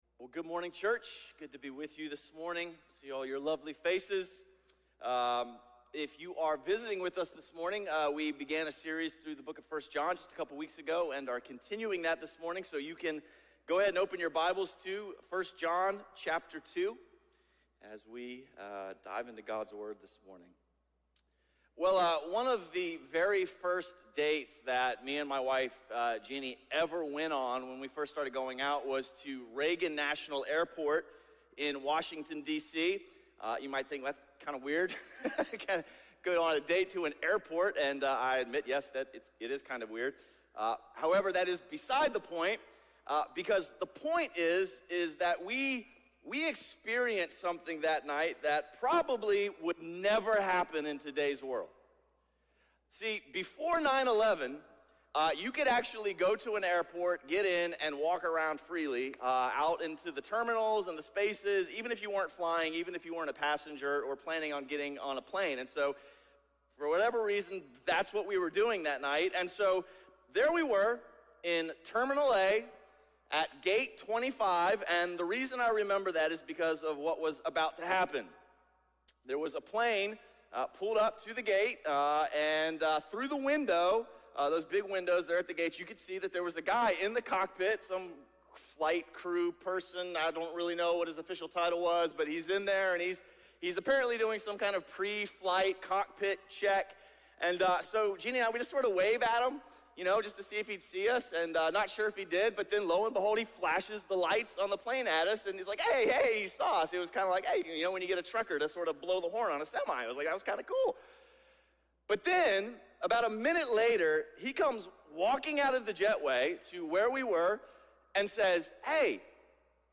A message from the series "Judges: {Un} Faithful."